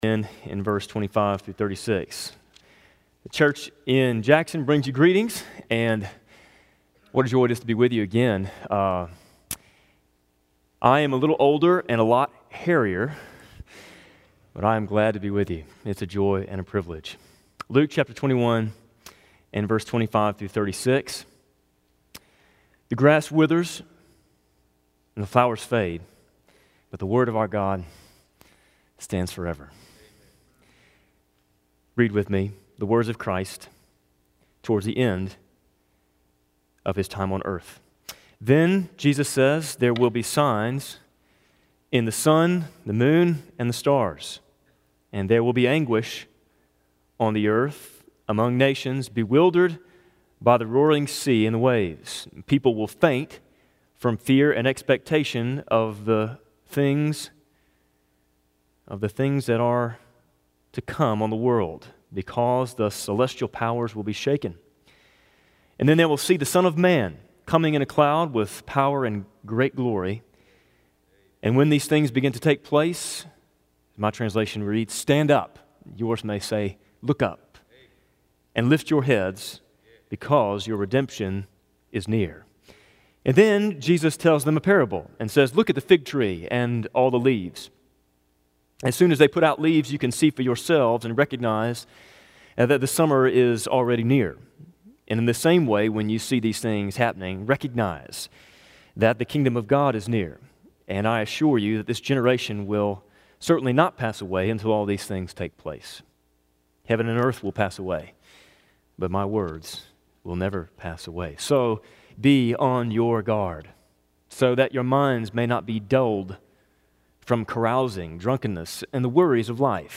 Guest Speaker
Sermon